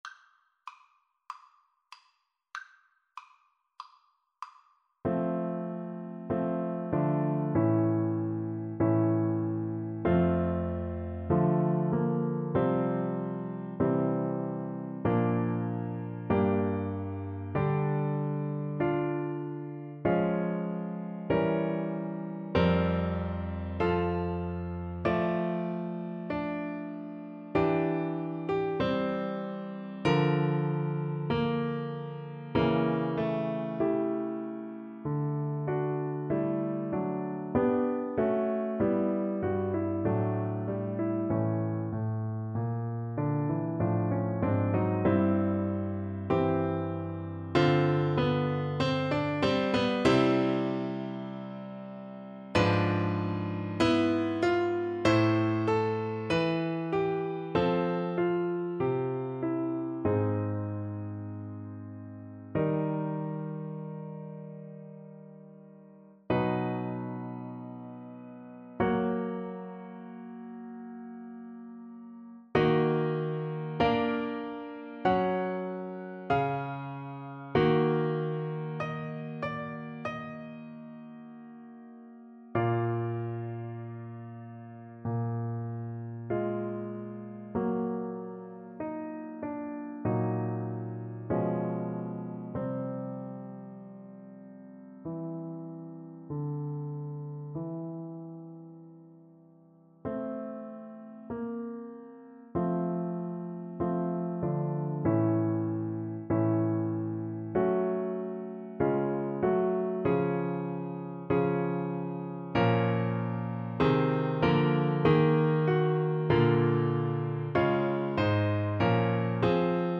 Play (or use space bar on your keyboard) Pause Music Playalong - Piano Accompaniment Playalong Band Accompaniment not yet available transpose reset tempo print settings full screen
~ = 96 Alla breve. Weihevoll.
F major (Sounding Pitch) (View more F major Music for Flute )
Classical (View more Classical Flute Music)